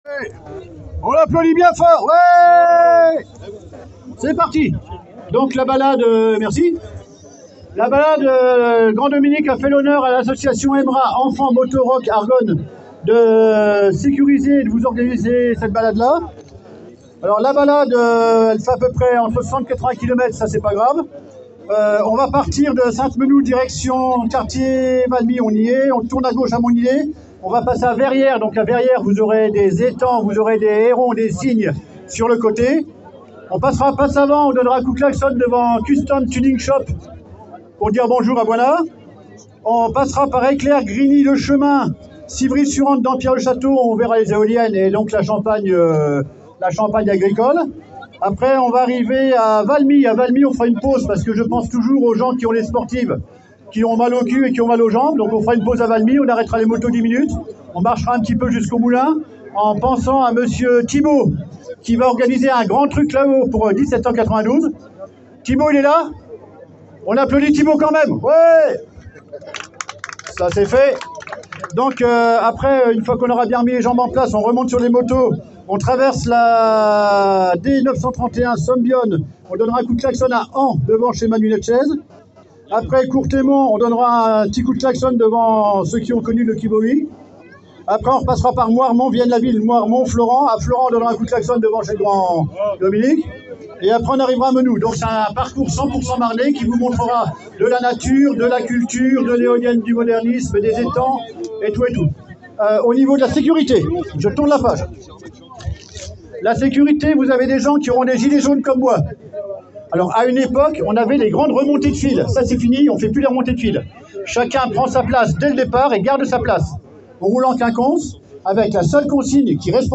1445 briefing avant roulage = les consignes (finies les remontées de file !!!)